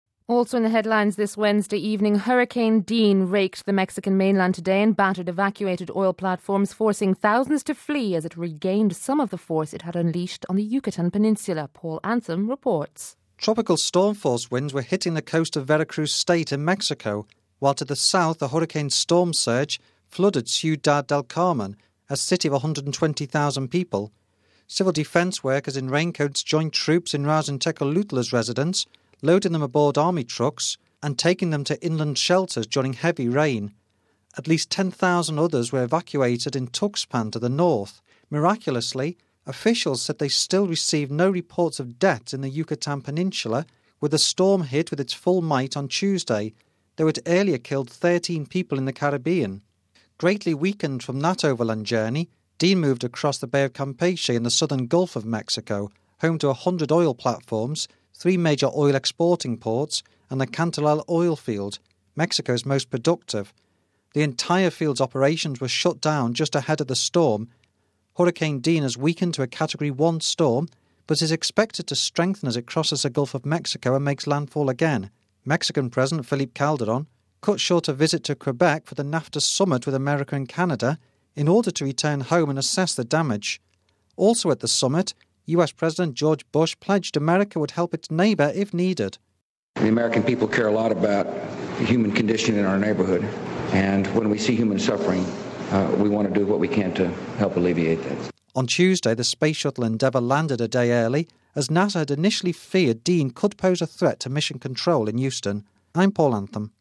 reports